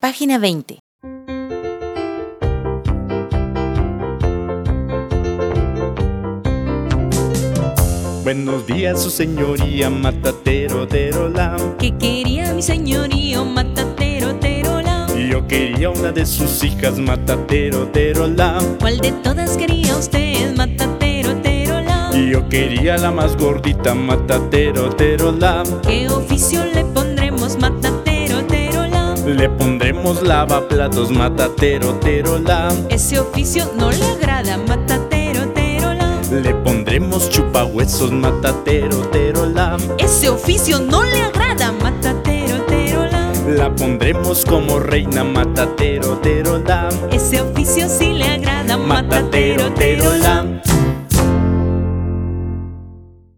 ronda tradicional